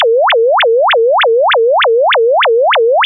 (Fig. 5a): Representative of a ground truth of the signal we should expect at the receiver. The receiver sampling frequency was 16 kHz.